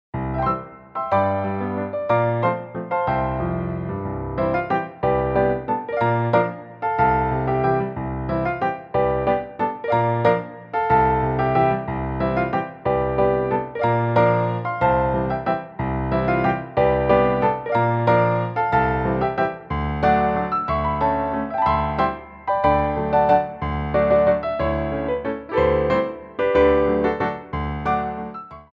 Grand Allegro
3/4 (16x8)